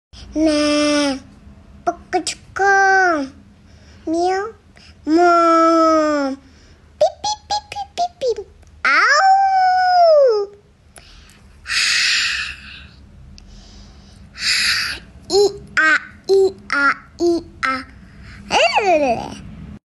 Baby Making Animal Sound… Cute Sound Effects Free Download